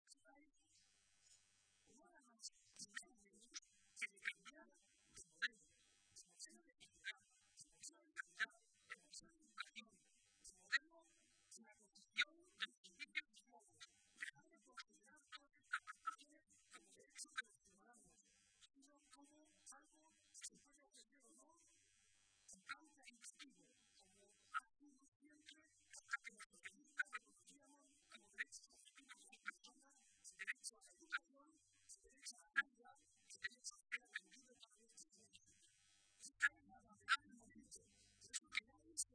“Hemos trasformado más la sociedad que el propio partido y el partido que ha trasformado la sociedad ahora se tiene también que trasformar para estar a la altura de esa nueva sociedad”, señaló Barreda durante su intervención en la tradicional comida navideña de los socialistas de la provincia de Toledo a la que asistieron más de 1.000 personas.
Intervención de Barreda